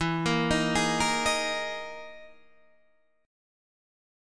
E Major 7th Flat 5th